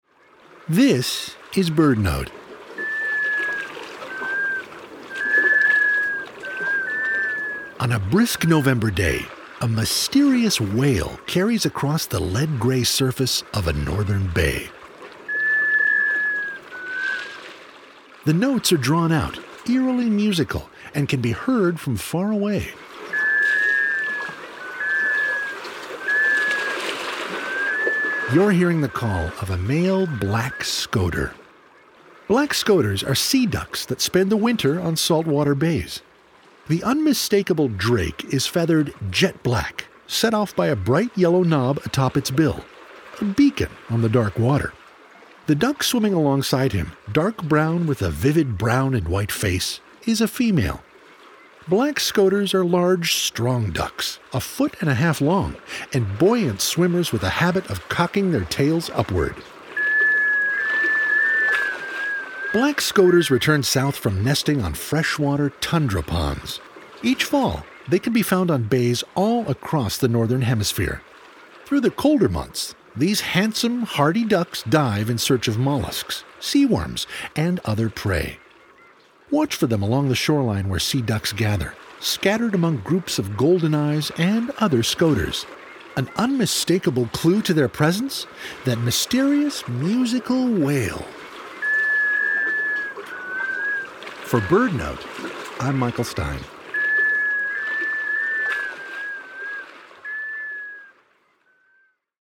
Their mysterious, musical wail.